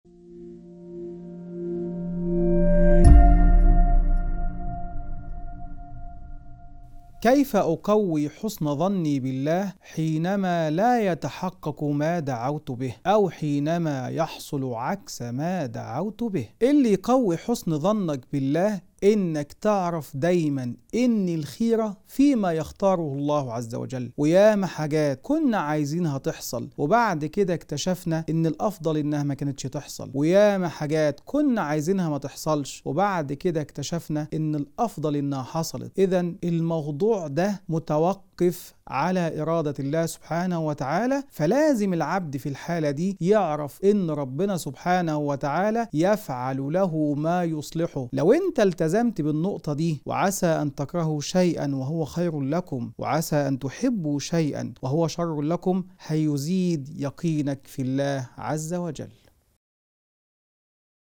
نص وعظي يشرح كيفية تقوية الظن بالله وزيادة اليقين به، حتى عندما لا تستجاب الدعوات أو تحدث عكس المطلوب. يؤكد على حكمة الله وأن الخير فيما يختاره لعباده، داعياً إلى الرضا والتسليم لقضائه.